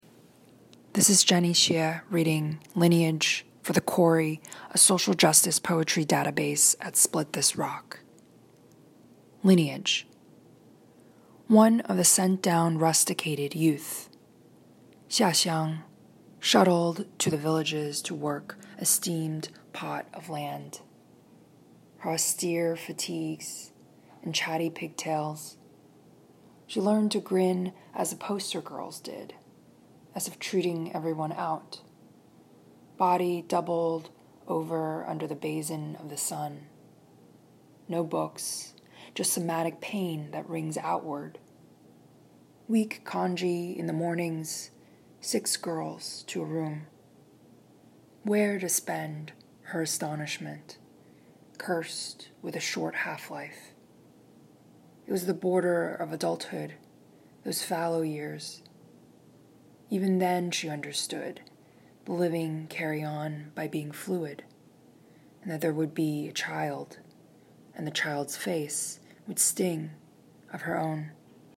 Listen as Jenny Xie reads "Lineage."